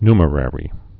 (nmə-rĕrē, ny-)